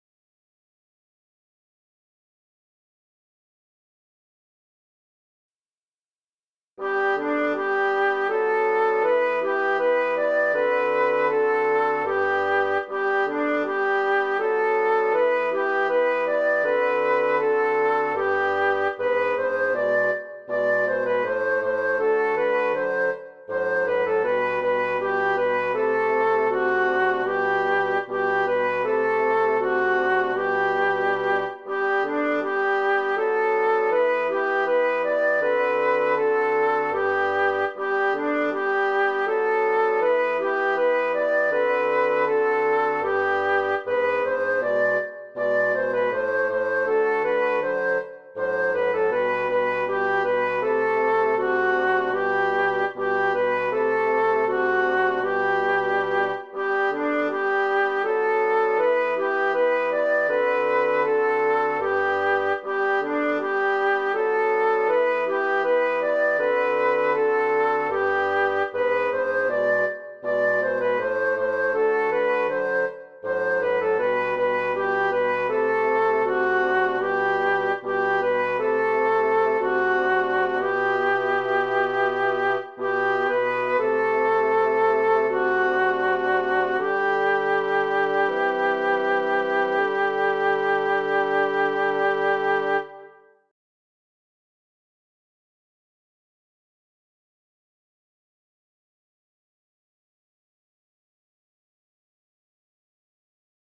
MP3 version instrumentale (les audios sont téléchargeables)
Soprano